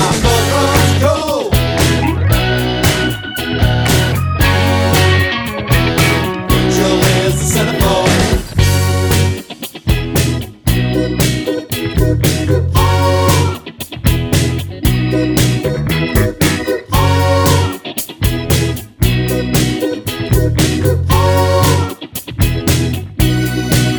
One Semitone Down Pop (1980s) 3:32 Buy £1.50